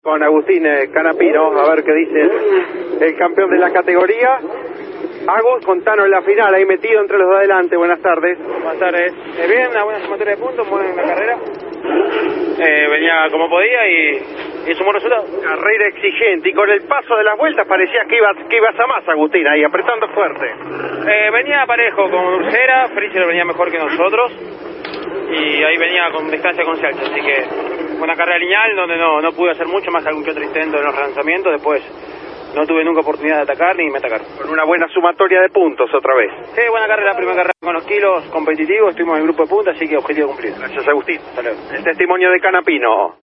Mantuvo el Chevrolet Camaro del Canning Motorsport entre las mejores posiciones, sosteniendo el 5° lugar de la carrera hasta el final y contando a Campeones su balance, por Radio Continental AM590.
CANAPINO EN CAMPEONES RADIO: